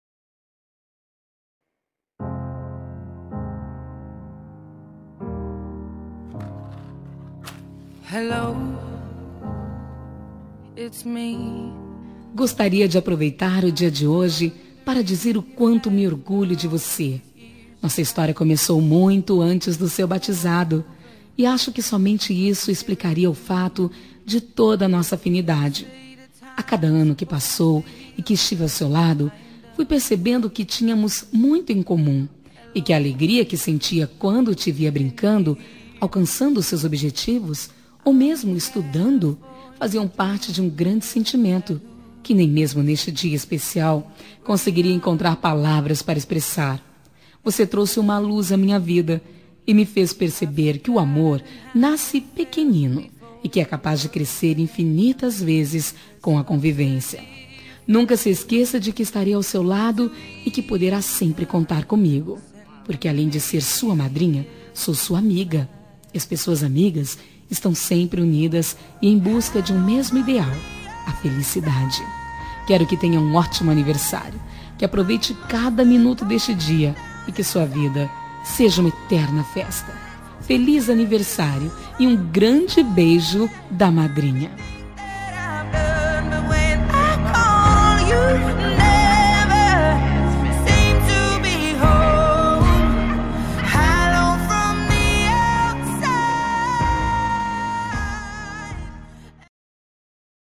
Aniversário de Afilhada – Voz Feminina – Cód: 2385